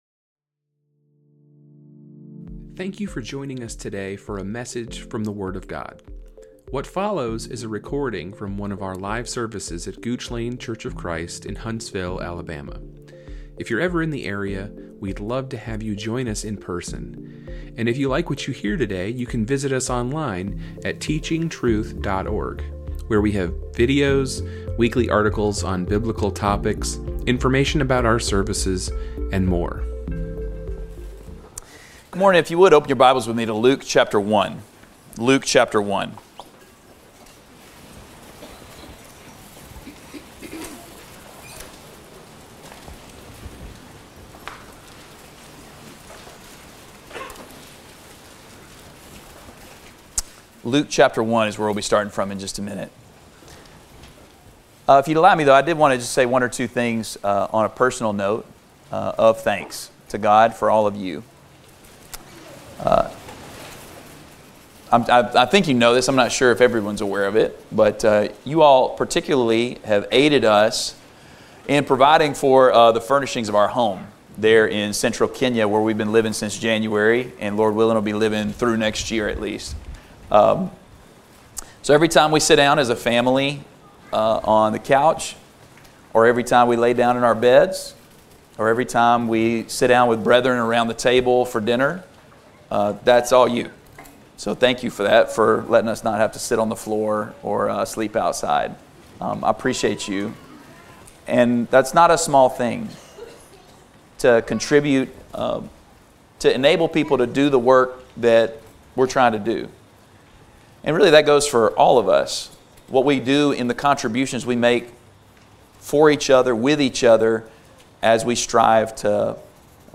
A lesson given on October 19, 2025.